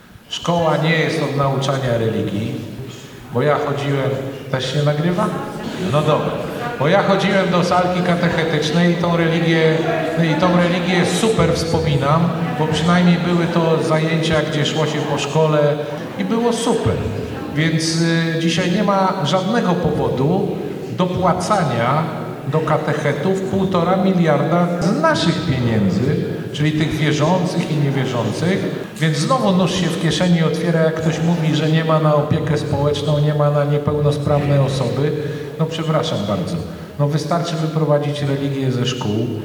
W Domu Kultury Kolejarza wiceprzewodniczący Nowej Lewicy Dariusz Wieczorek spotkał się z mieszkańcami Stargardu.